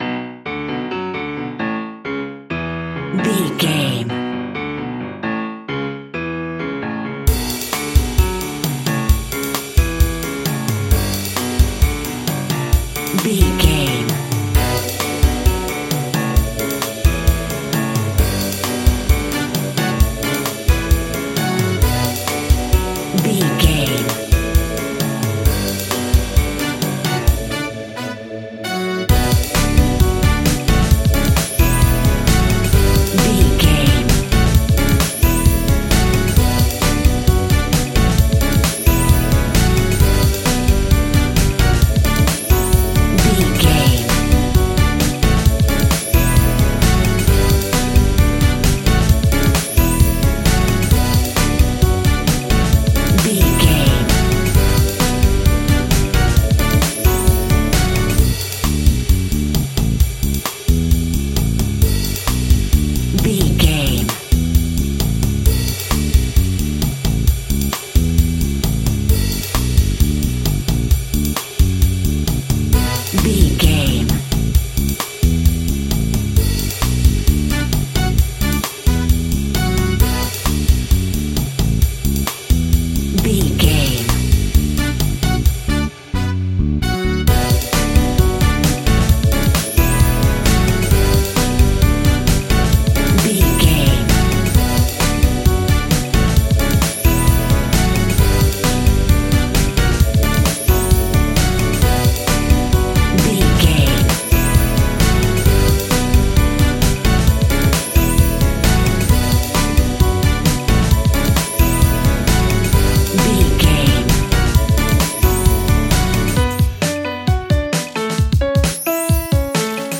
Aeolian/Minor
salsa
groovy instrumental music
energetic
electric guitar
bass guitar
drums
hammond organ
fender rhodes
percussion